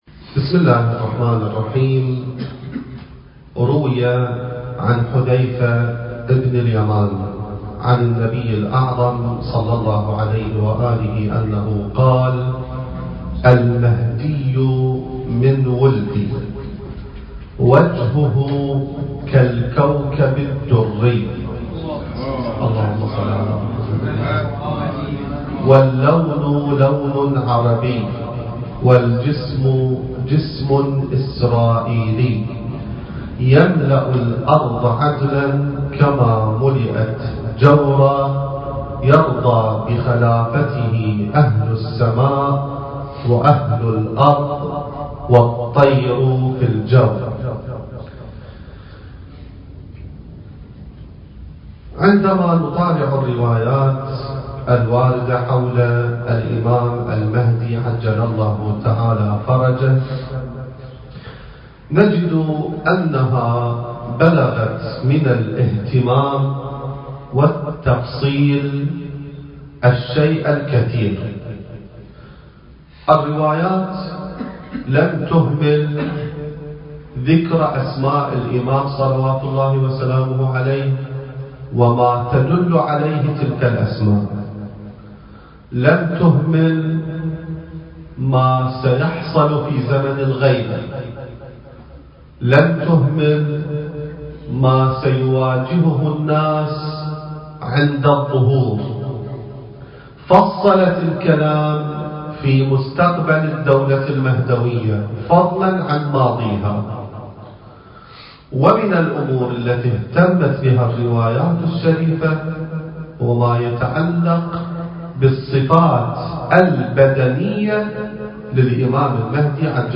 المكان: مؤسسة الإمام السجاد (عليه السلام) / استراليا التاريخ: 2019